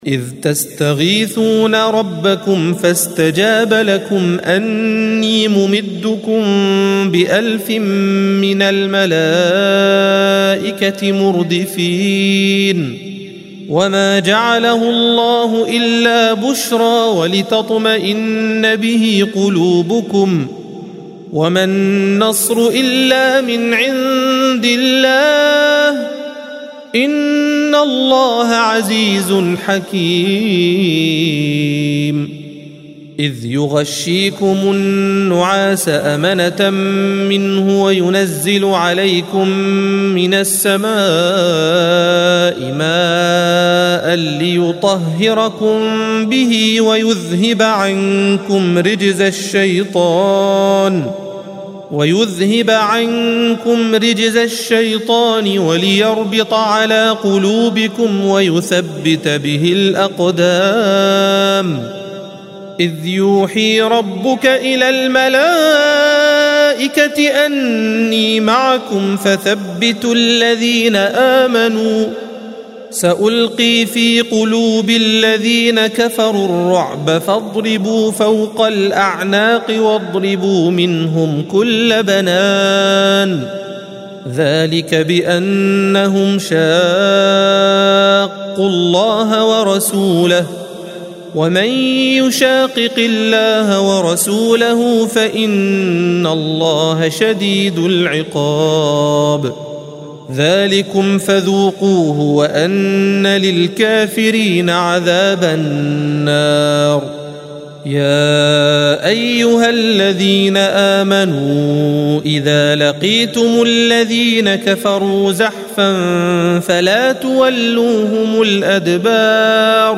الصفحة 178 - القارئ